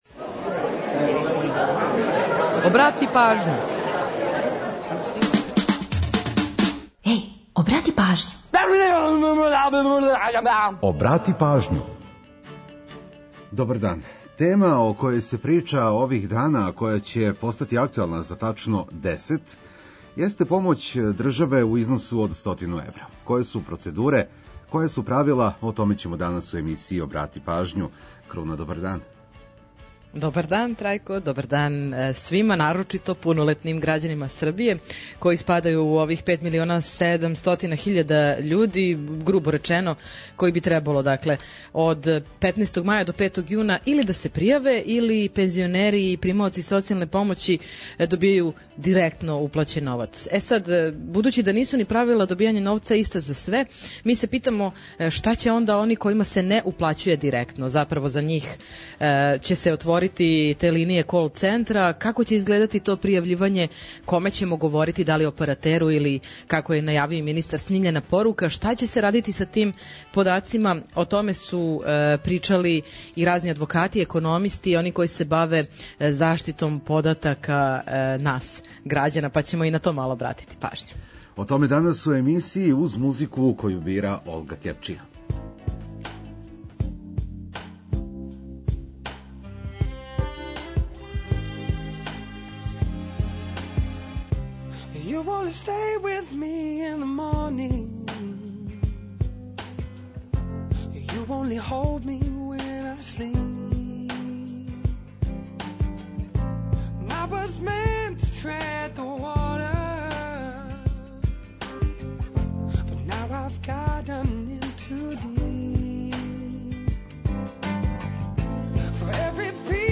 Ту је и пола сата резервисаних само за нумере из Србије и региона.